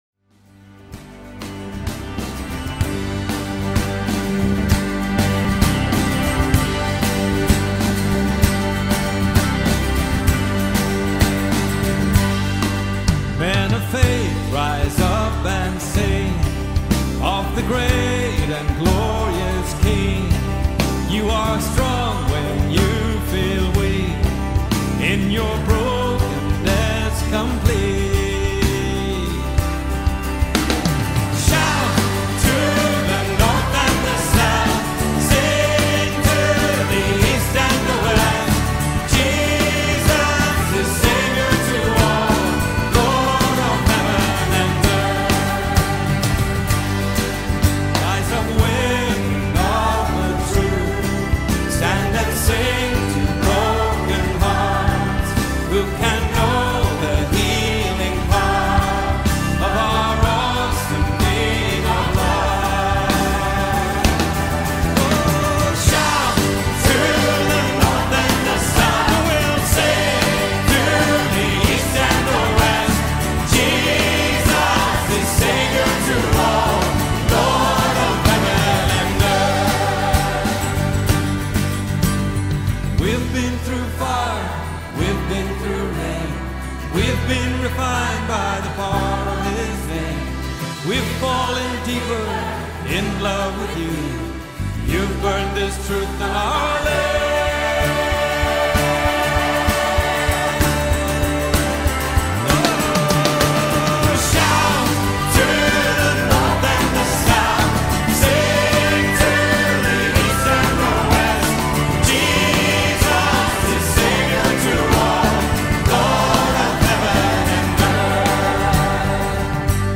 Welcome to this time of worship.